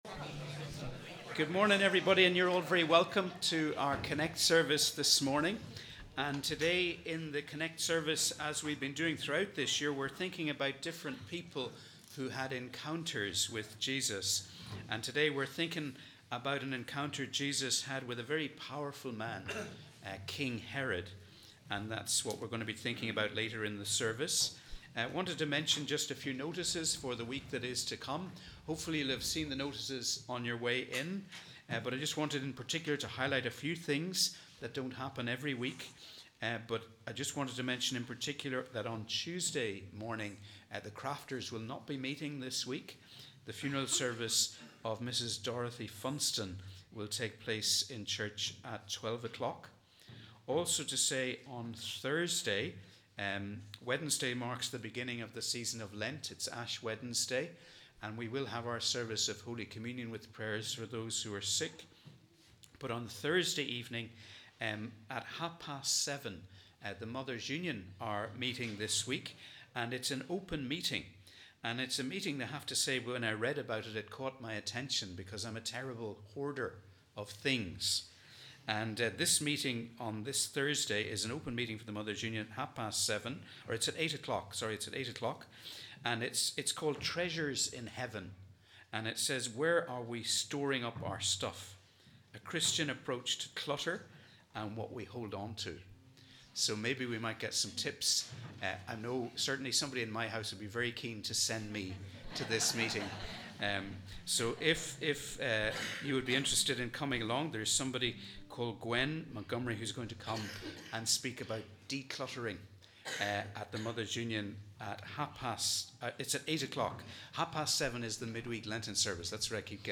We warmly welcome you to our CONNEC+ service as we worship together on the Sunday before Lent.